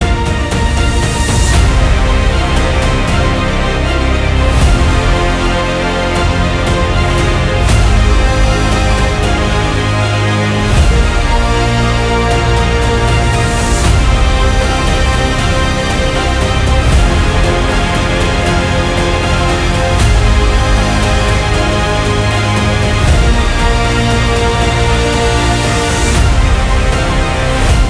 атмосферные
без слов
эпичные
Эпическая трейлерная музыка